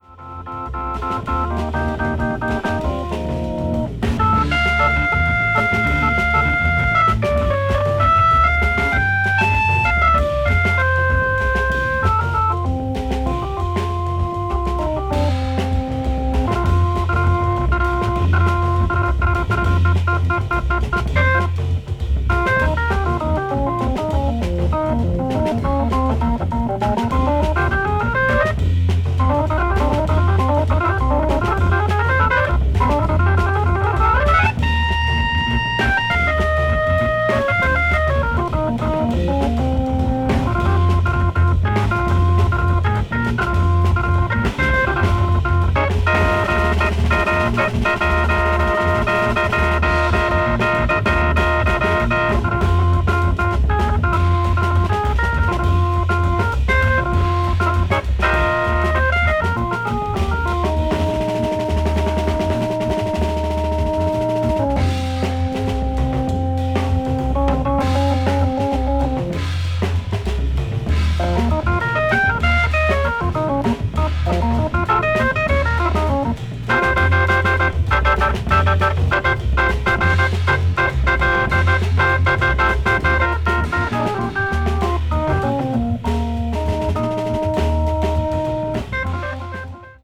avant-jazz   contemporary jazz   free jazz   spiritual jazz